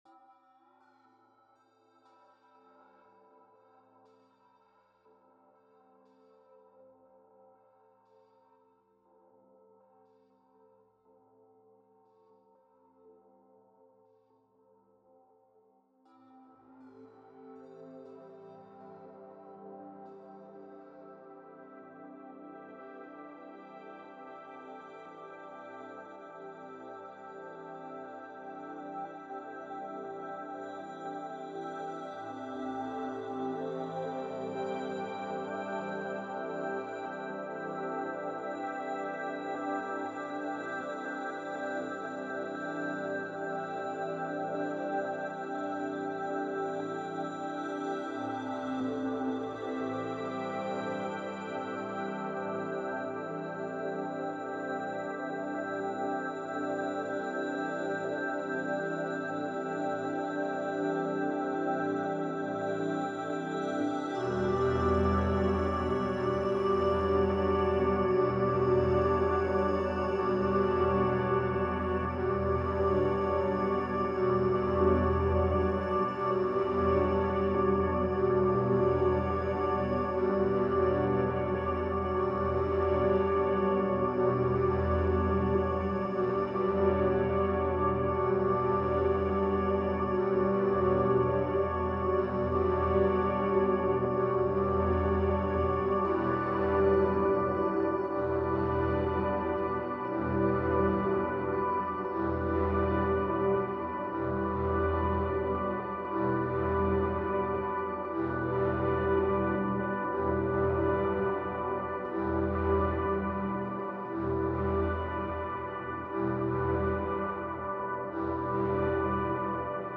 mangle synth